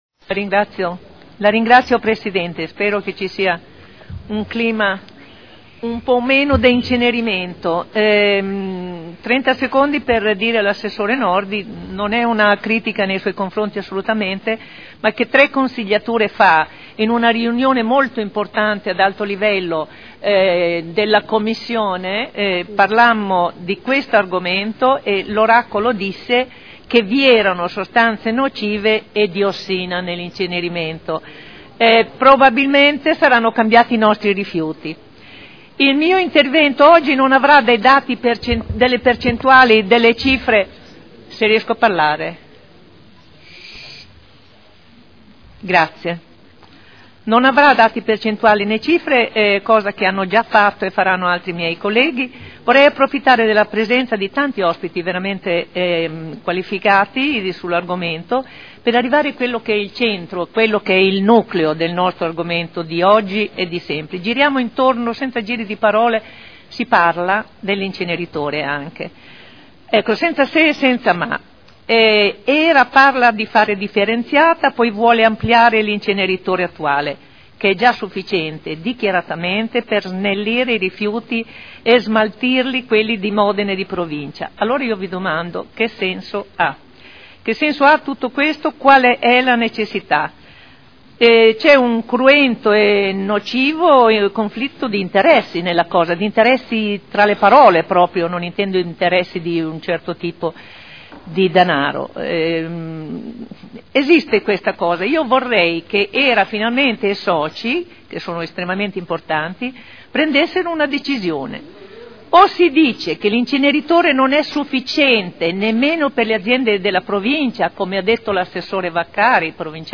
Olga Vecchi — Sito Audio Consiglio Comunale